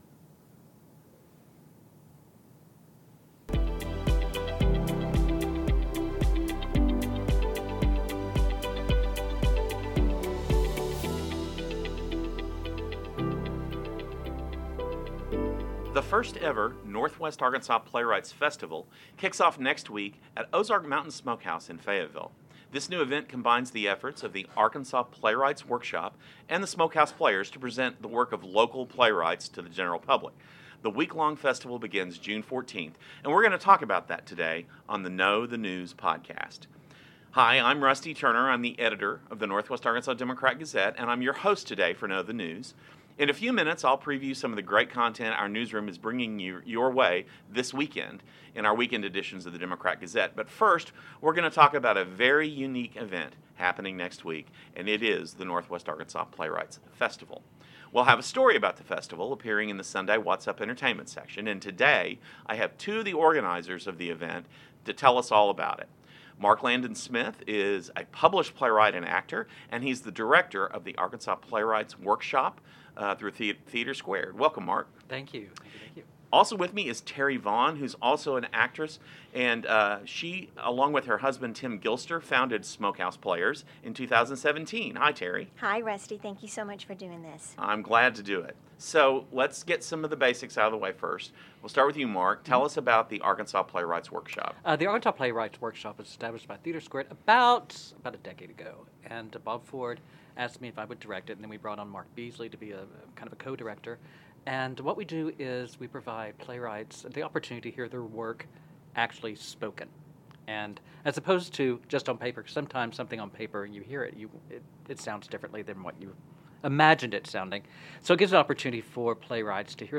Know-The-News-Interview-6-3.mp3